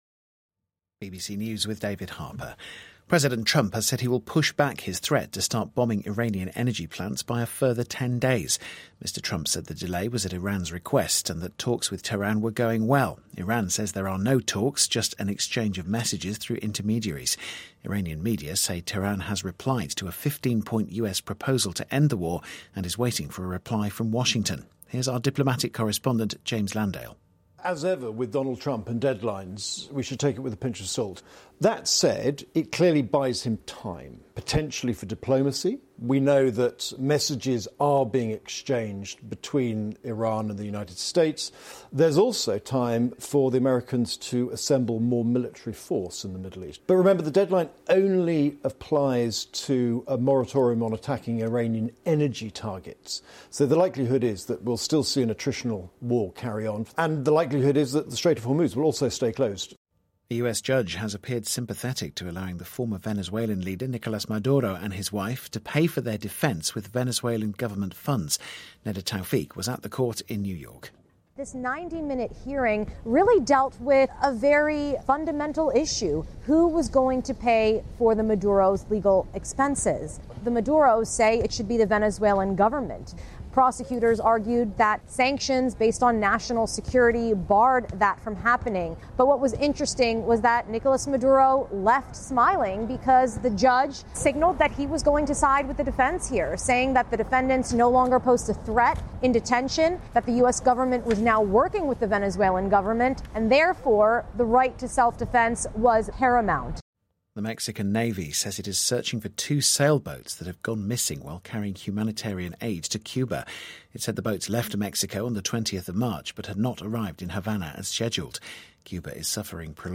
BBC新闻